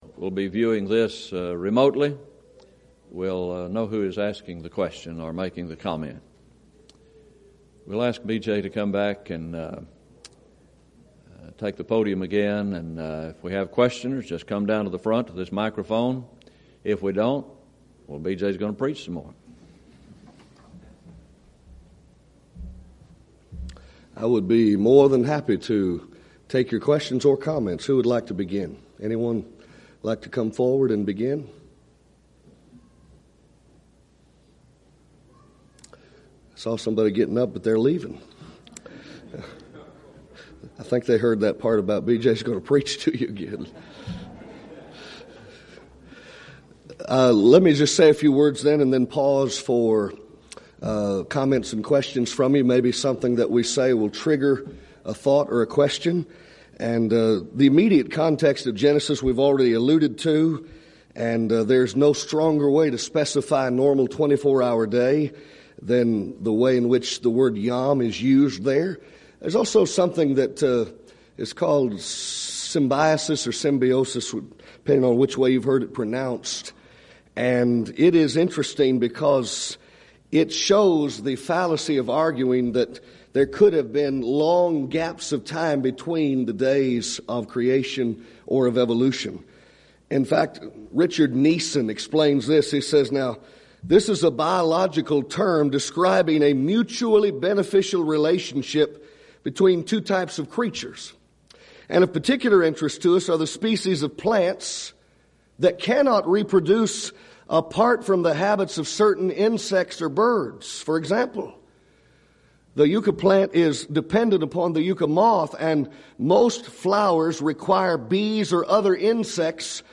Title: Tuesday Forum: Were the Days of Creation 24-Hour Days (Questions from Floor)
Event: 2nd Annual Schertz Lectures